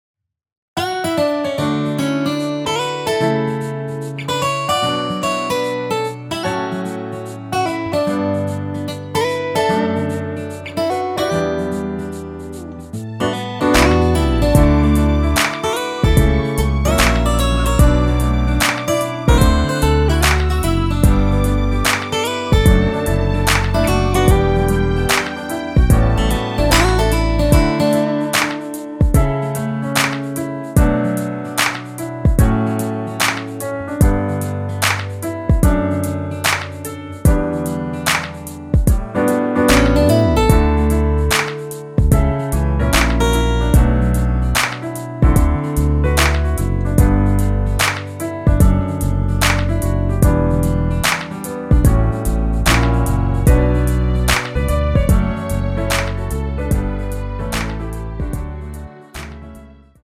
내린 MR입니다.
앞부분30초, 뒷부분30초씩 편집해서 올려 드리고 있습니다.
중간에 음이 끈어지고 다시 나오는 이유는